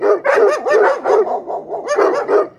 DOGS.mp3